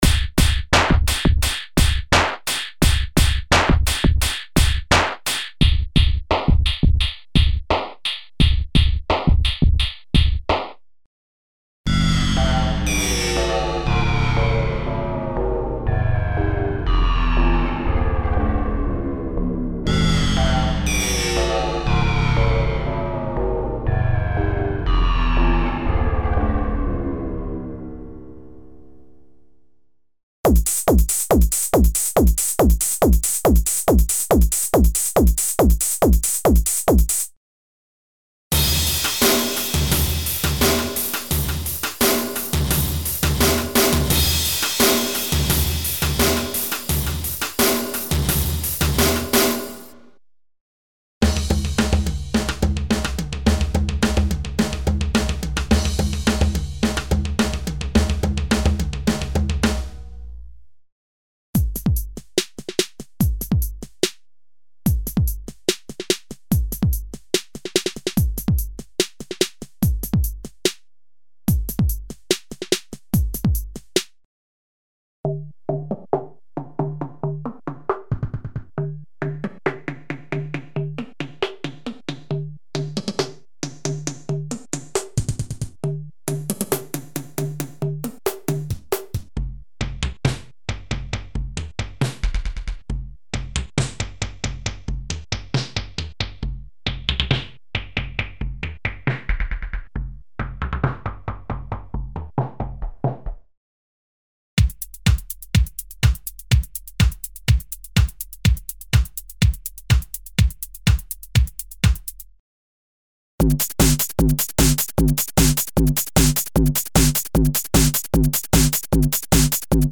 Acoustic, electronic and experimental drum & percussion kits created to take advantage of the special controller settings for internal DSP modulations (e.g. filter, pitch, shaper, distortion, etc.).
The collection is ideal for a wide variery of music styles, ranging from Jazz and Rock to Hip-Hop and underground electronic music.
Info: All original K:Works sound programs use internal Kurzweil K2661 ROM samples exclusively, there are no external samples used.